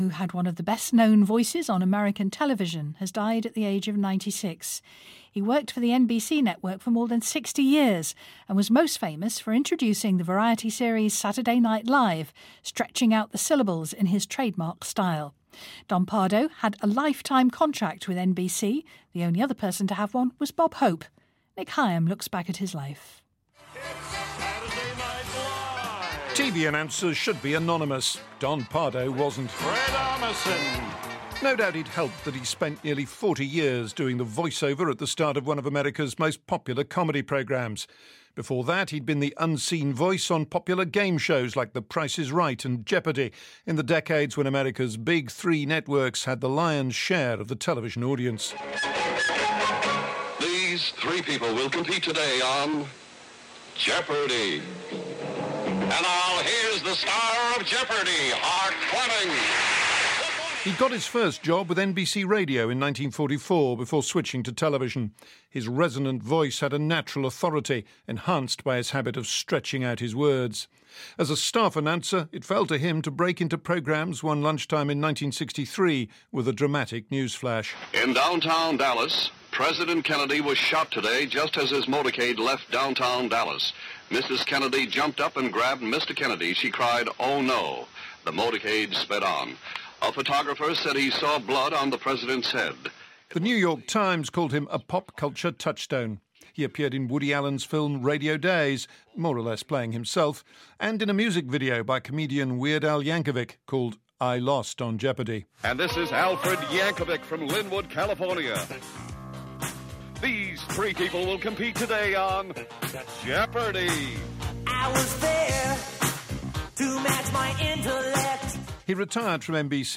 Nick Higham reports for BBC Radio 4